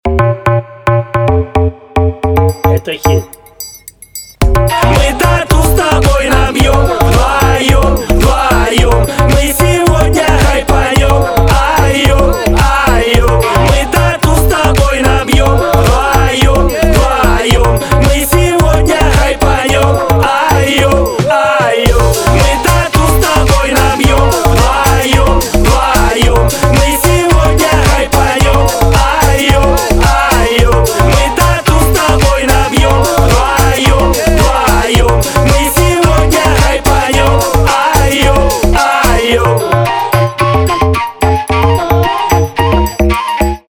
• Качество: 320, Stereo
ритмичные
Хип-хоп
заводные
dance
club
клубняк
Флейта
духовые